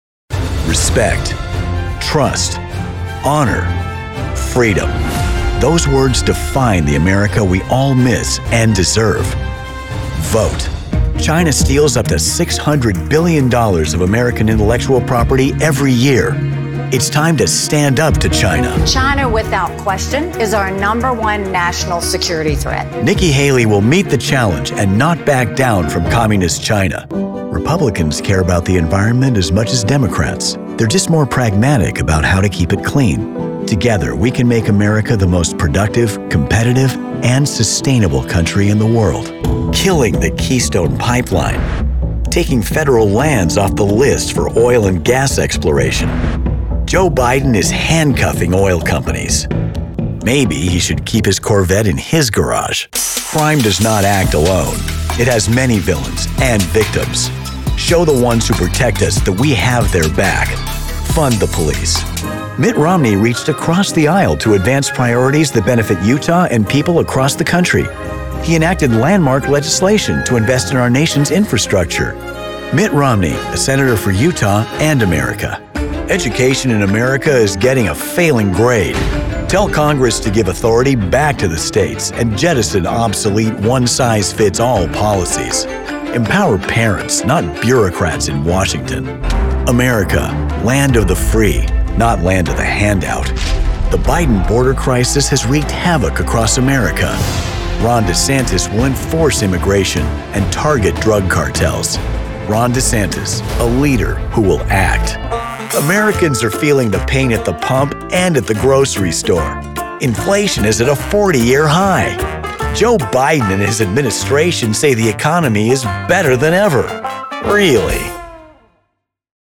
Political
Middle Aged
Microphone: Sennheiser MKH416
Acoustically treated home recording space